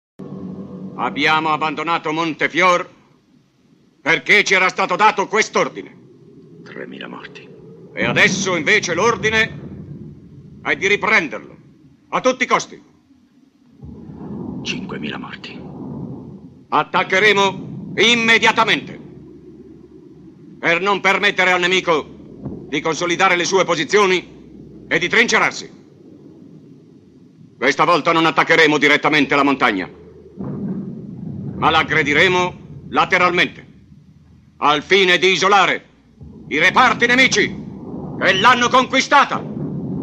dal film "Uomini contro", in cui doppia Alain Cuny.